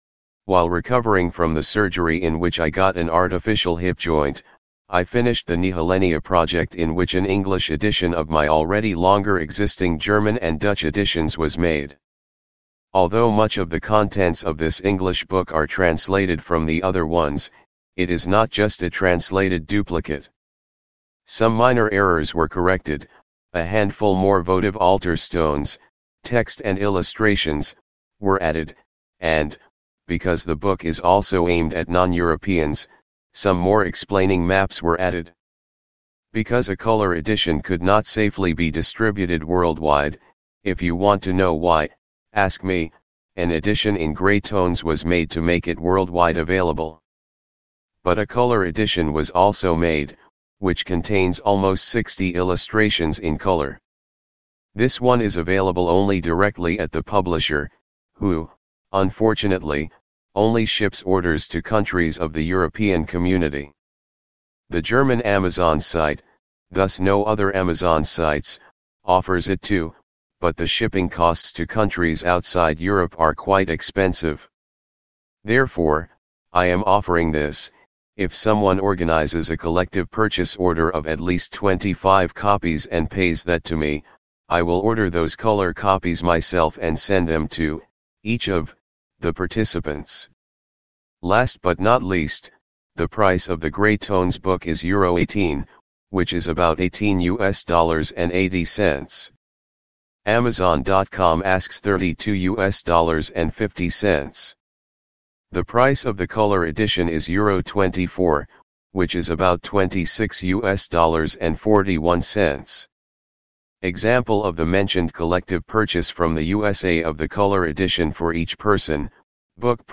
Or listen to this post, which was generated using the VOVSOFT Text to MP3 Converter